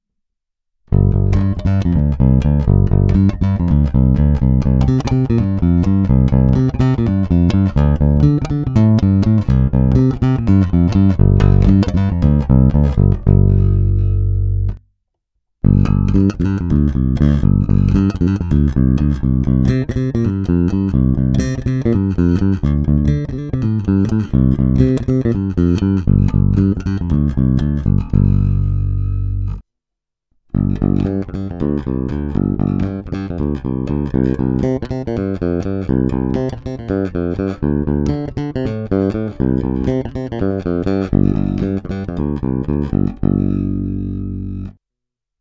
Pevný, kovově vrnící, poměrně agresívní, prosadí se.
Není-li uvedeno jinak, následující nahrávky jsou provedeny rovnou do zvukové karty, s plně otevřenou tónovou clonou a bez zařazení aktivní elektroniky.